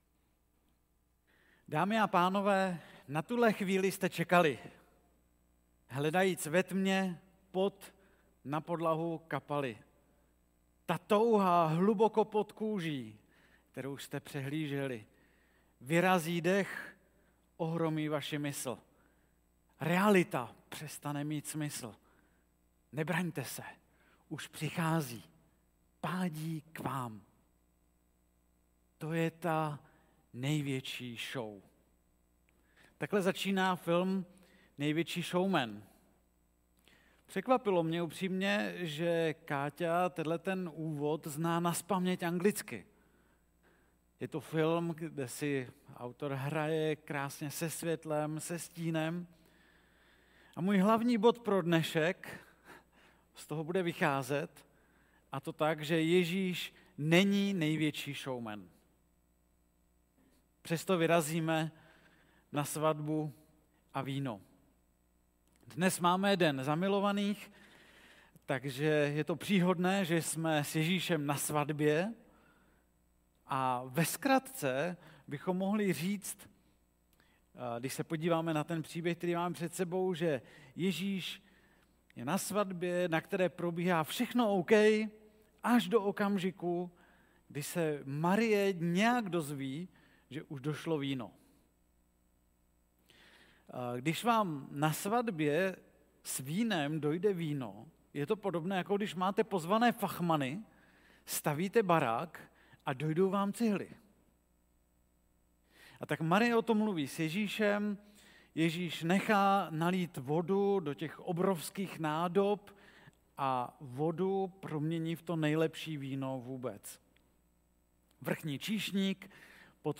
4. kázání ze série Záblesky slávy (Jan 2,1-12)
Kategorie: Nedělní bohoslužby